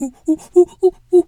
monkey_2_chatter_08.wav